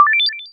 success-chime.mp3